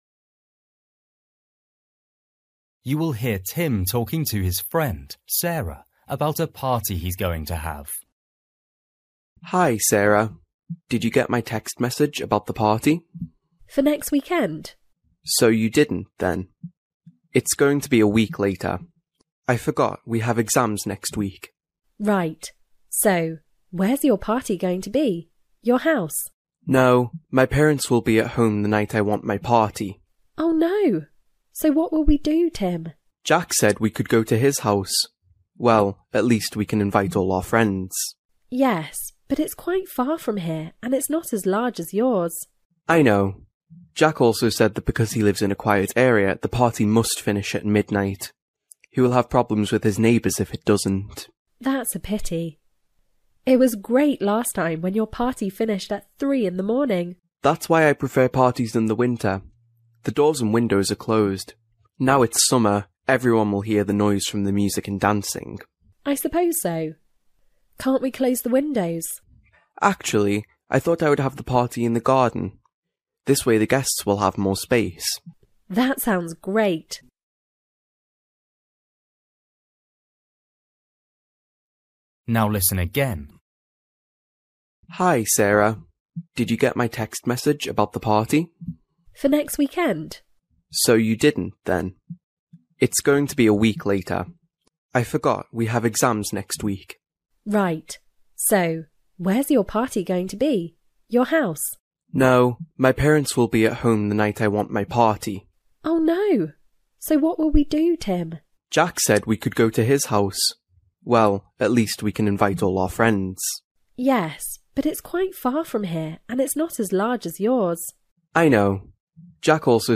Bài tập trắc nghiệm luyện nghe tiếng Anh trình độ sơ trung cấp – Nghe một cuộc trò chuyện dài phần 17